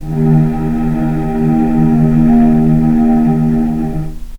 vc-D#2-pp.AIF